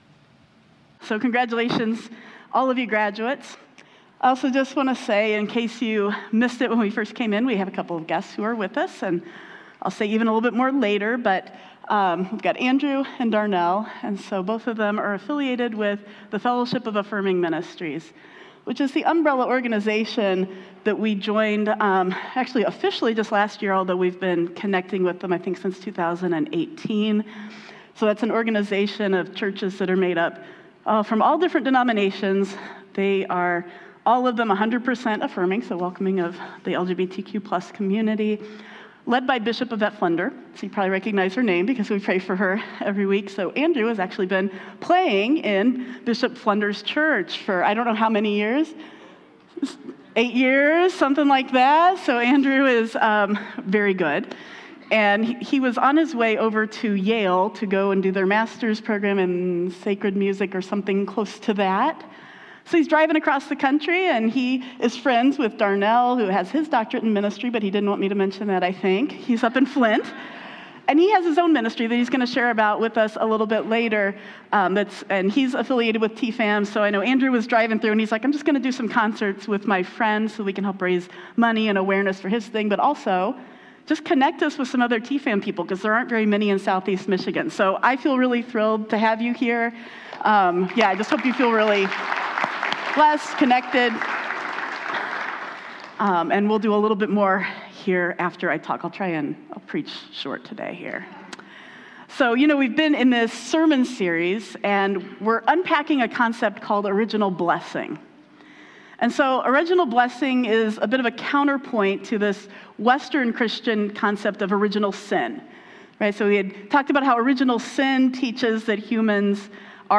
Also includes a musical performance by special guests